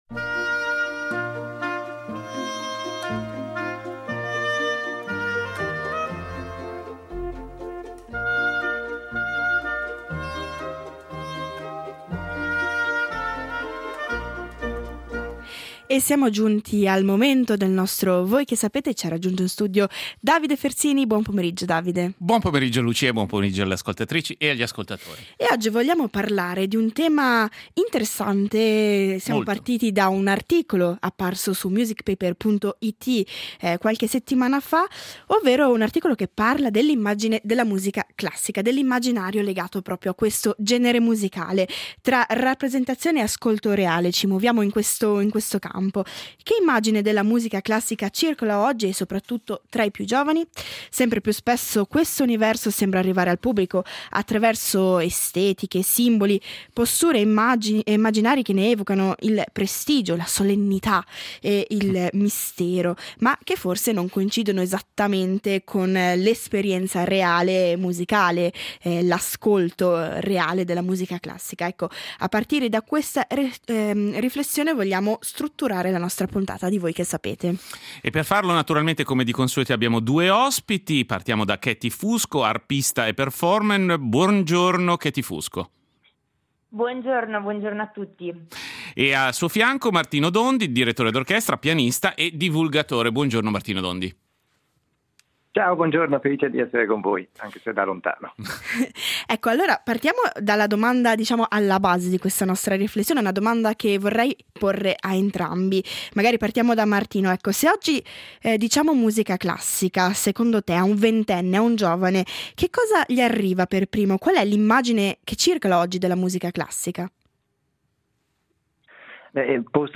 A partire da una recente riflessione pubblicata da “MusicPaper” sul modo in cui la classica viene raccontata e percepita fuori dai suoi contesti tradizionali, Voi Che Sapete apre uno spazio di discussione su linguaggi, rappresentazione, divulgazione e nuove possibilità di incontro tra mondi musicali diversi.